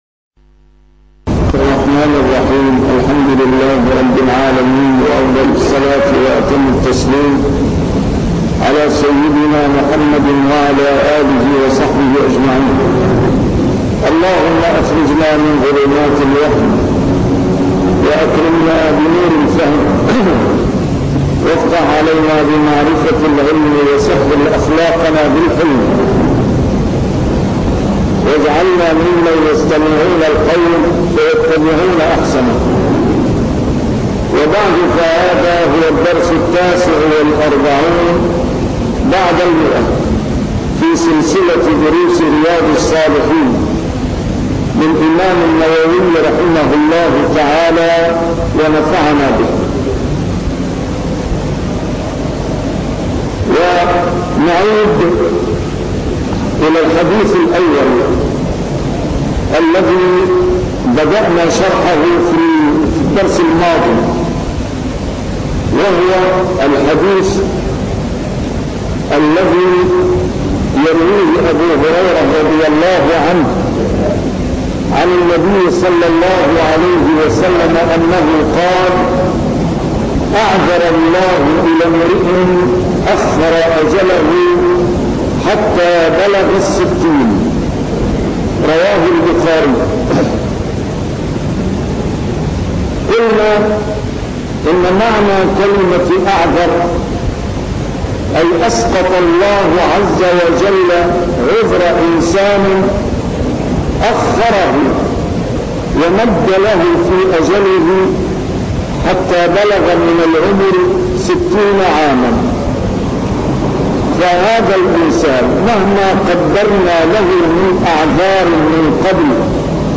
نسيم الشام › A MARTYR SCHOLAR: IMAM MUHAMMAD SAEED RAMADAN AL-BOUTI - الدروس العلمية - شرح كتاب رياض الصالحين - 149- شرح رياض الصالحين: الازدياد من الخير